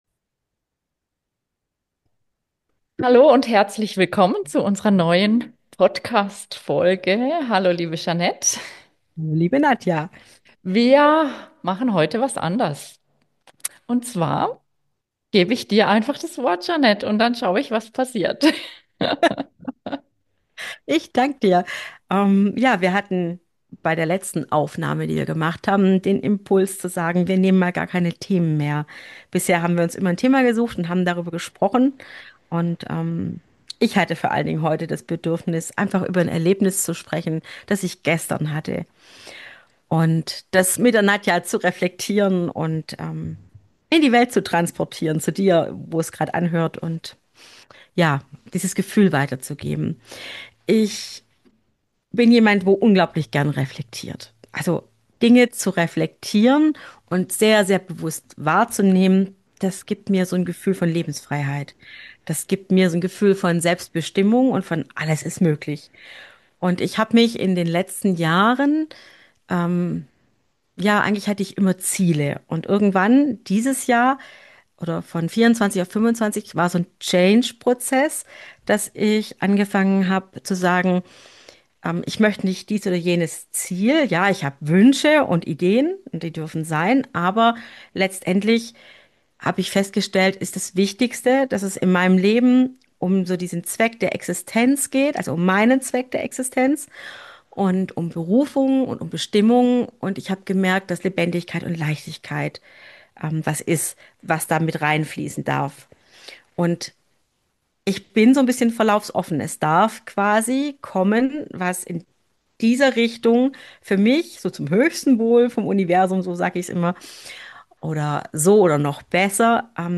Diese Episode ist ein Gespräch zwischen zwei Frauen, die einander zuhören, sich spiegeln und gemeinsam erforschen, wie ein bewusstes Leben in Leichtigkeit möglich wird.